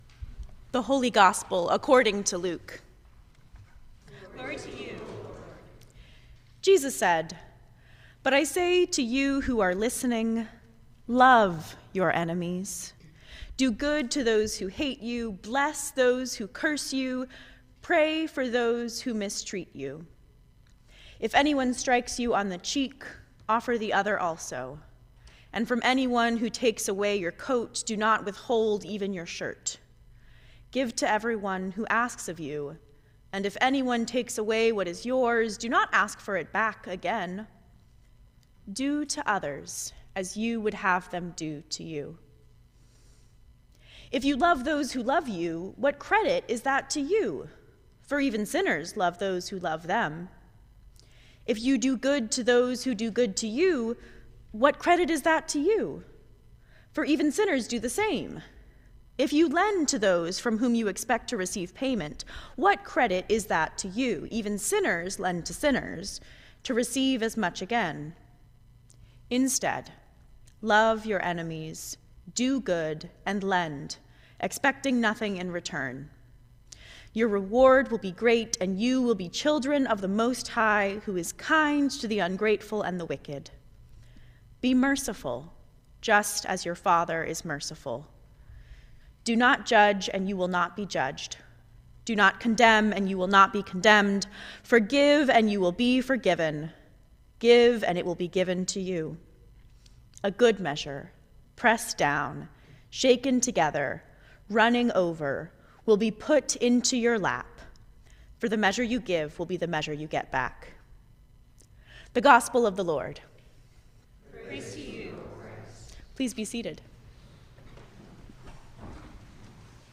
Sermon for the Seventh Sunday after Epiphany 2025